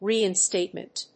発音記号
• / ˌriɪˈnstetmʌnt(米国英語)
• / ˌri:ɪˈnsteɪtmʌnt(英国英語)